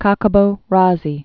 (käkə-bōzē)